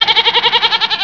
Click on the squares in the barn and identify the sound of the animal.
dr0sgoat.wav